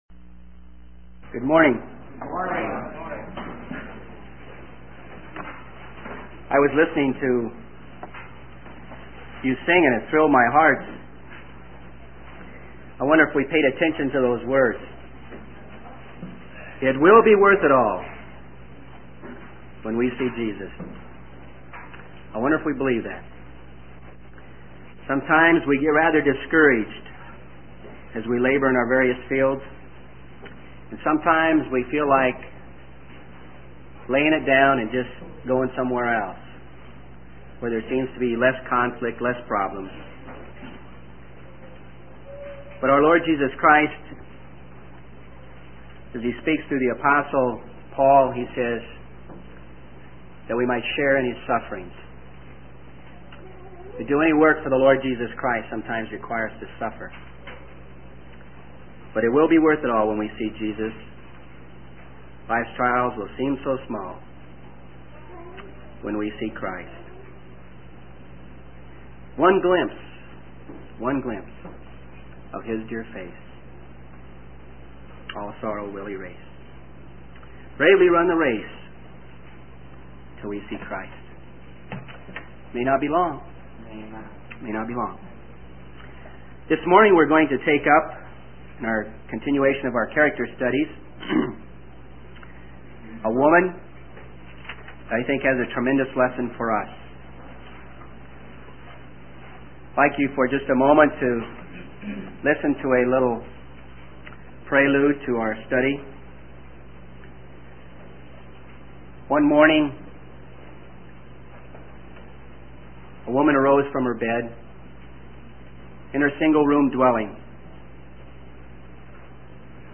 In this sermon, the preacher focuses on the story of a poor widow who gave two small copper coins in the temple treasury.